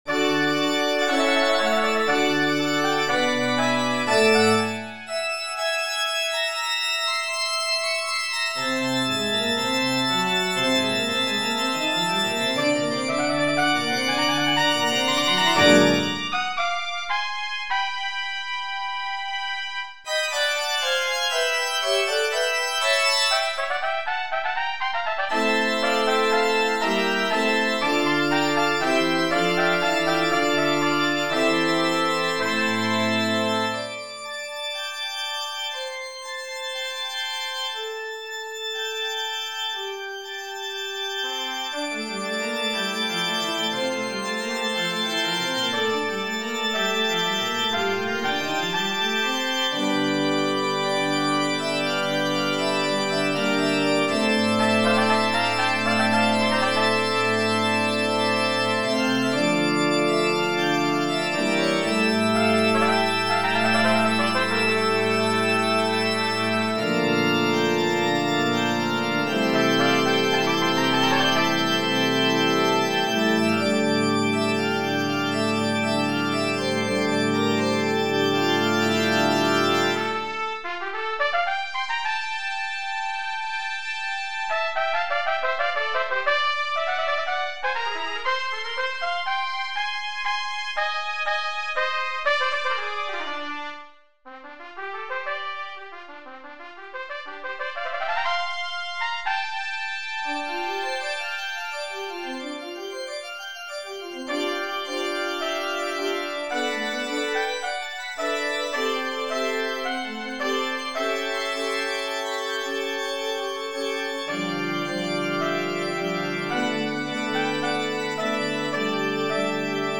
• 2 Trumpets and Organ
for 2 trumpets and piano